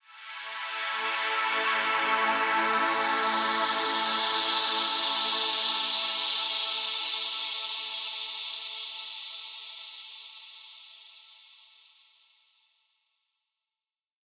SaS_HiFilterPad01-A.wav